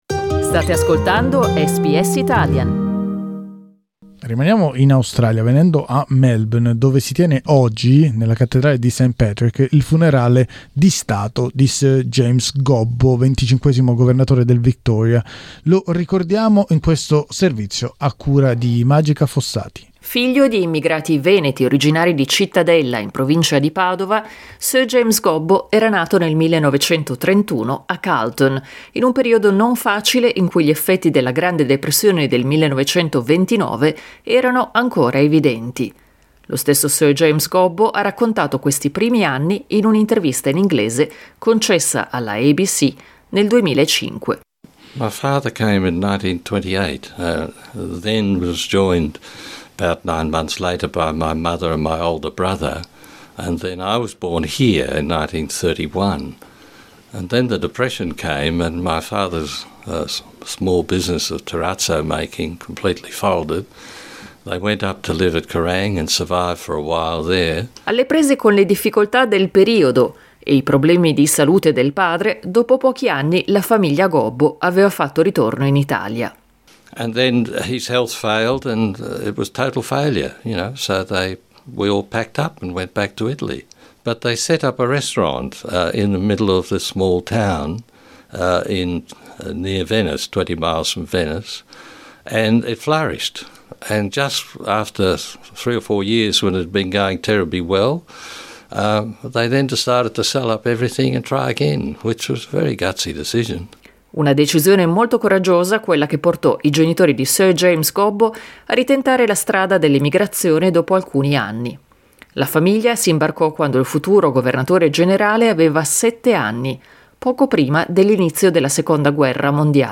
READ MORE Da Cittadella a Carlton, la straordinaria vita di Sir James Gobbo In questo servizio riascoltiamo alcuni estratti di un'intervista concessa all'ABC, in cui lo stesso Sir James ricorda alcuni momenti importanti della sua vita, mentre Marco Fedi, attuale presidente del Coasit di Melbourne, sottolinea l'importanza che l'attività comunitaria dell'ex governatore ha avuto per gli italo-australiani.